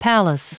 Prononciation du mot palace en anglais (fichier audio)
palace.wav